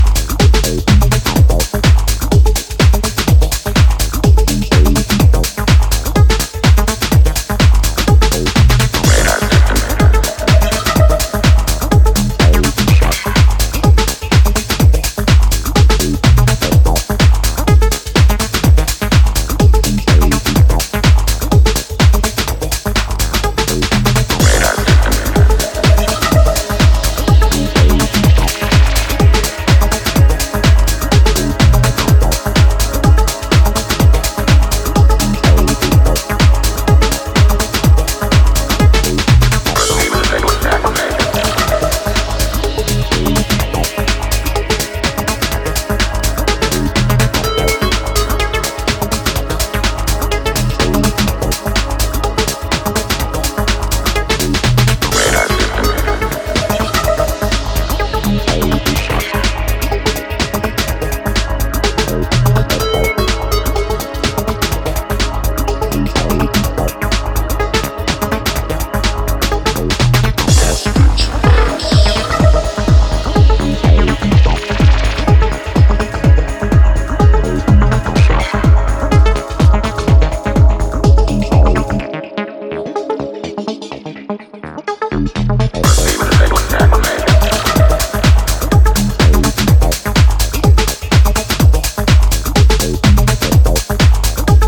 acid lines